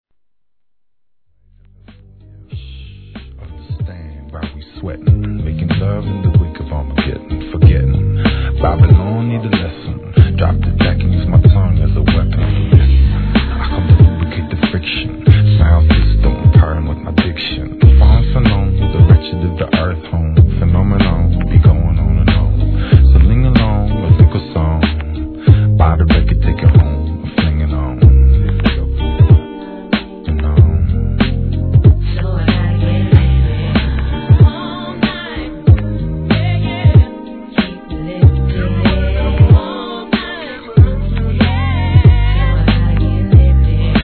HIP HOP/R&B
女性ヴォーカルが絡む'97年HIP HOP!!!